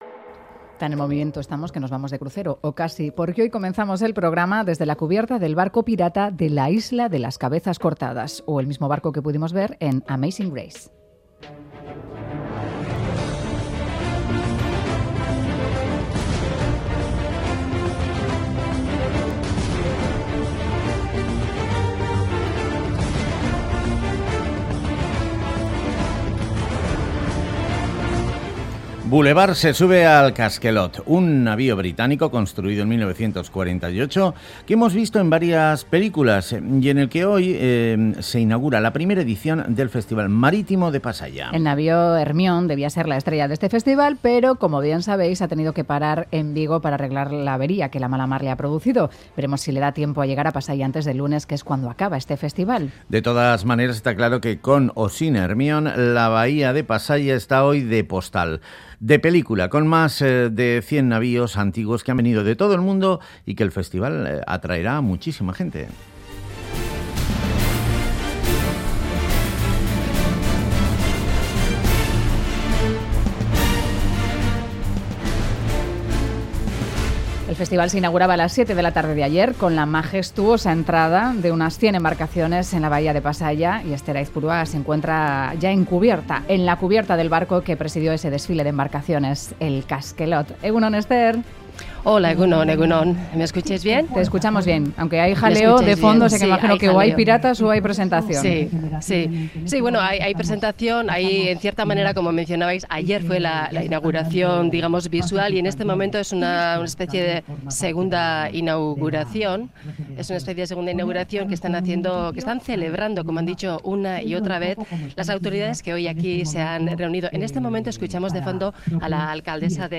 Audio: En 'Boulevard' nos hemos acercado al primer Festival Marítimo de Pasaia para ver el ambiente in situ y disfrutar con las diferentes embarcaciones que se han acercado hasta el puerto gipuzcoano.